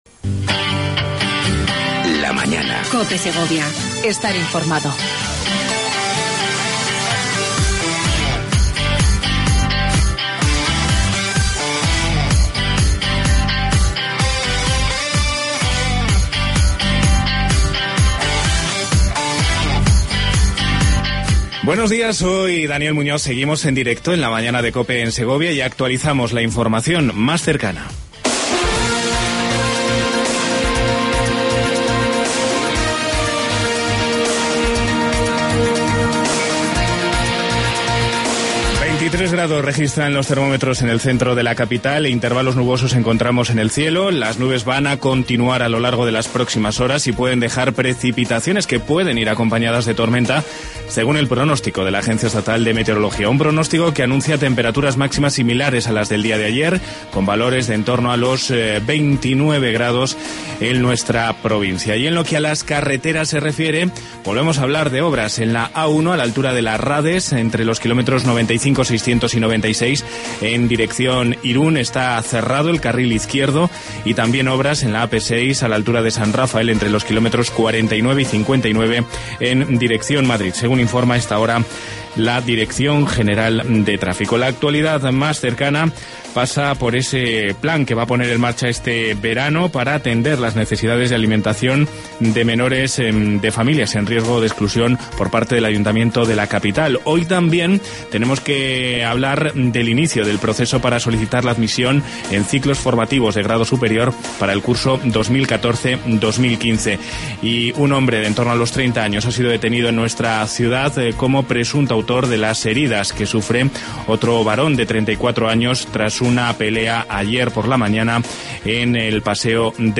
AUDIO: Entrevista con Javier Lopez Escobar, delegado territorial de La Junta de Castilla Y León.